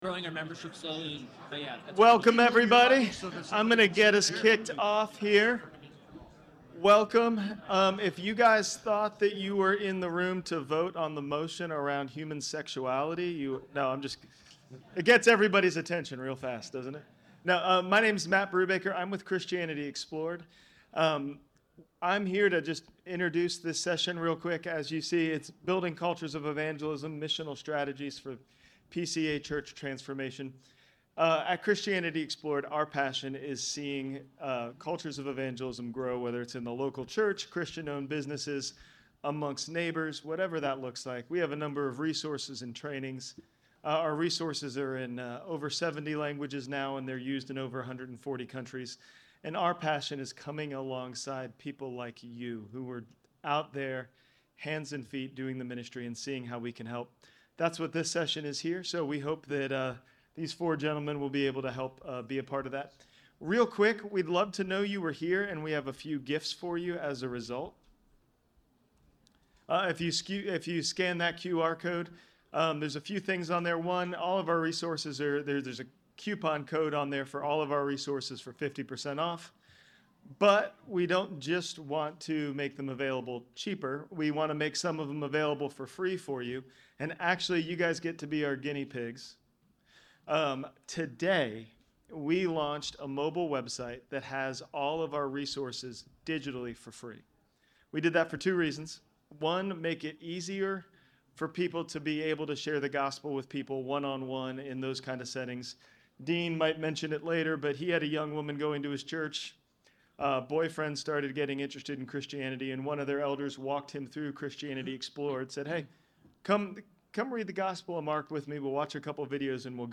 The panel will present adaptable approaches suitable for various PCA contexts, equipping leaders to confidently champion evangelism and discipleship within our denomination, ultimately inspiring a renewed commitment to gospel-centered ministry Listen Online Here